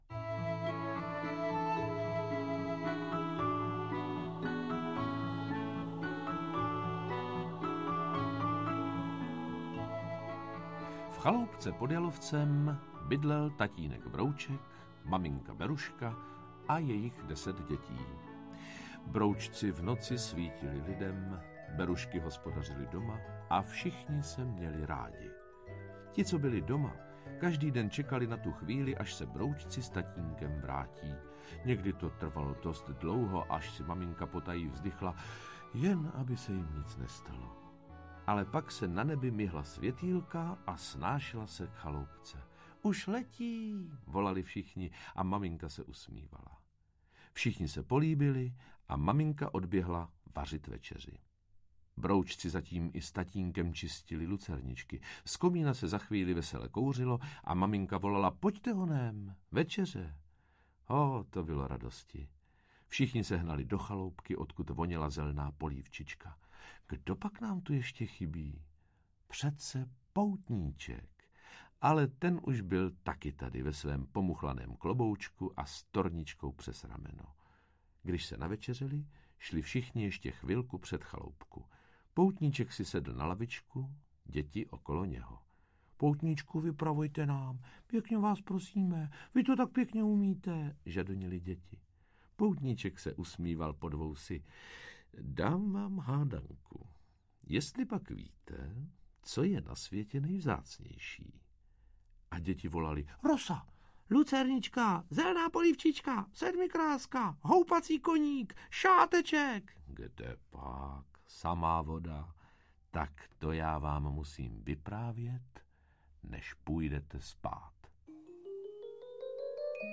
Broučci 2 - Dobrodružství na pasece audiokniha
Ukázka z knihy